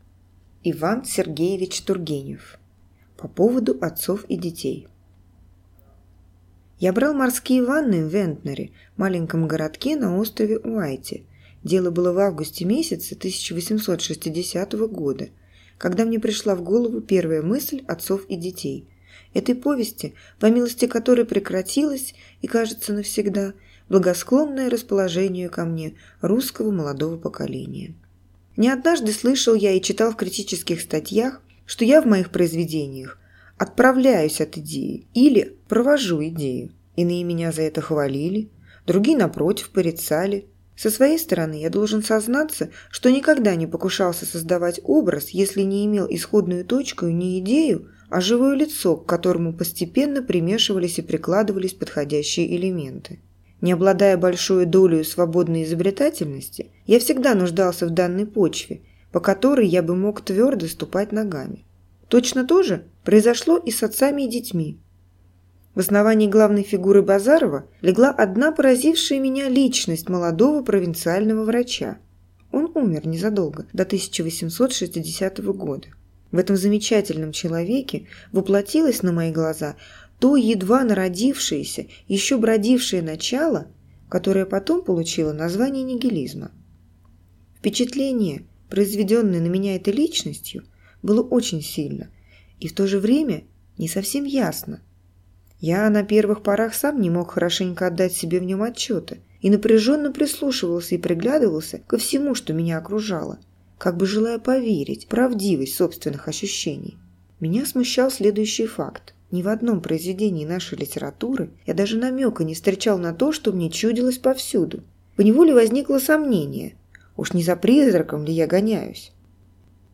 Аудиокнига По поводу «Отцов и детей» | Библиотека аудиокниг